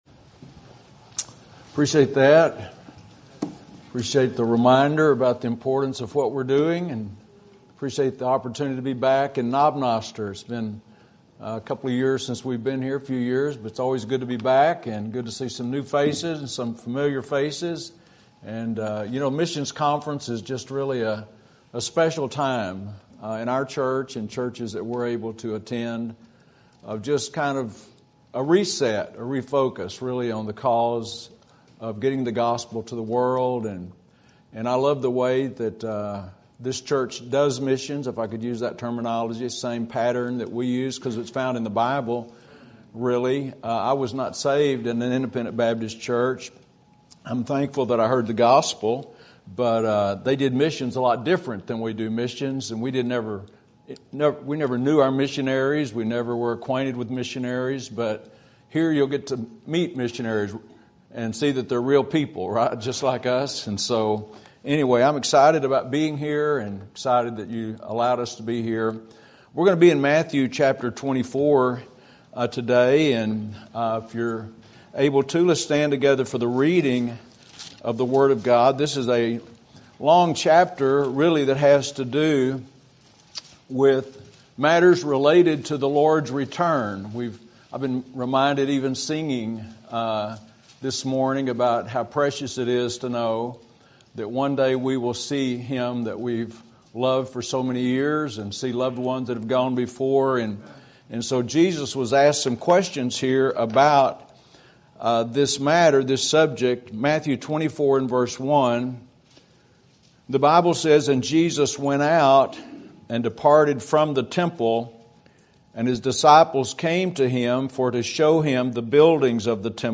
Sermon Topic: Missions Conference Sermon Type: Special Sermon Audio: Sermon download: Download (18.61 MB) Sermon Tags: Matthew Missions Noah Salvation